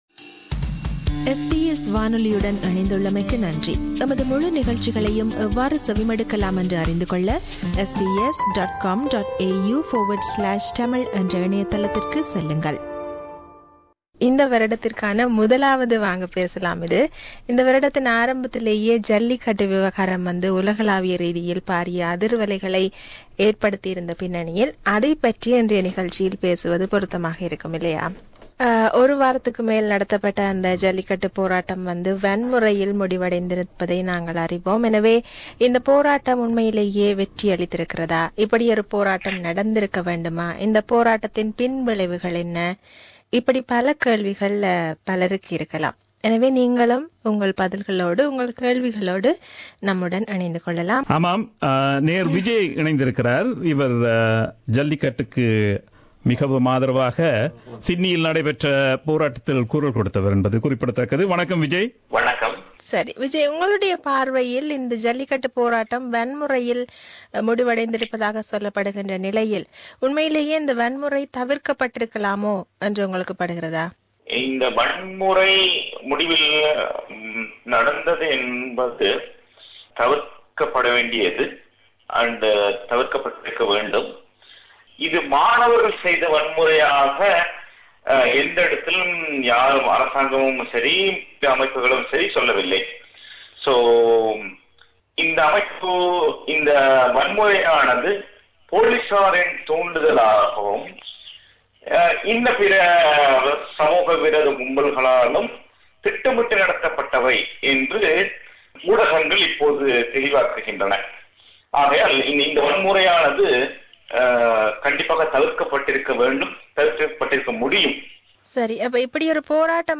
This is a talk back on Jallikattu.